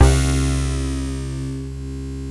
BUZZBASSC2-R.wav